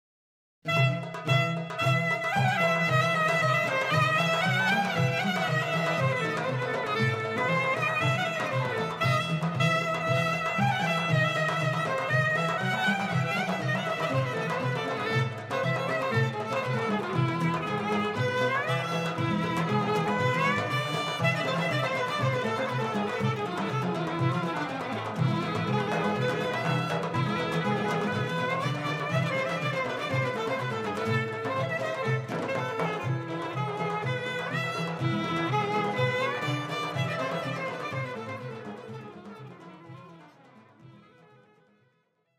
Genres: Turkish Traditional, Roman.
clarinet
violin
kanun
darbuka
Recorded on November 3rd, 1999 in Istanbul at Audeon Studios